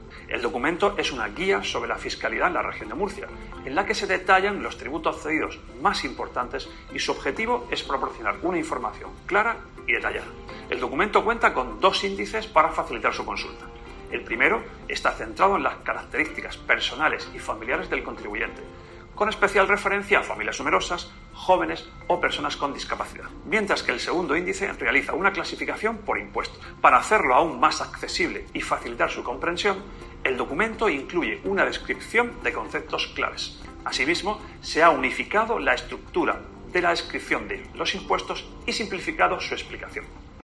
Luis Alberto Marín, consejero de Economía, Hacienda, Fondos Europeos y Administración Digital